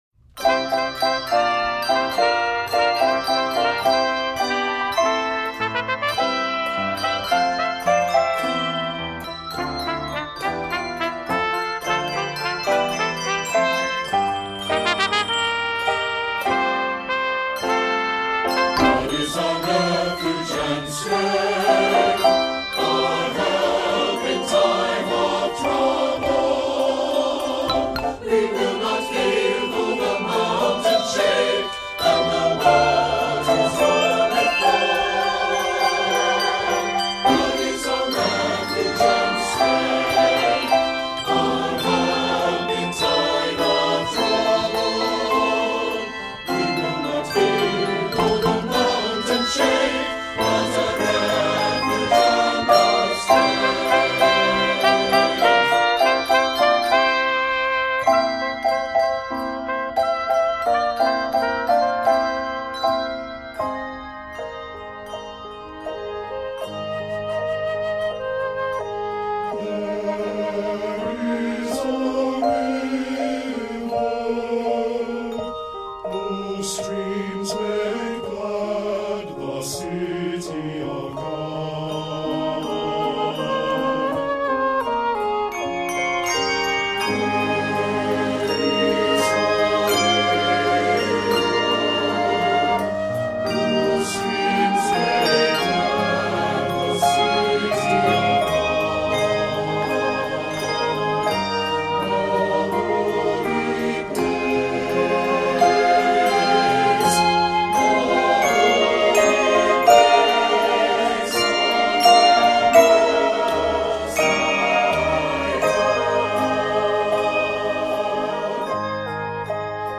original handbell setting